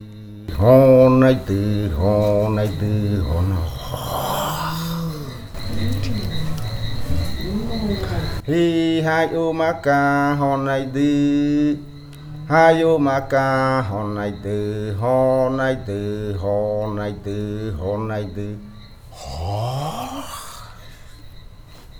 Leticia, Amazonas, (Colombia)
Grupo de danza Kaɨ Komuiya Uai
Canto de fakariya de la variante jaiokɨ (cantos de culebra).
Fakariya chant of the Jaiokɨ variant (Snake chants).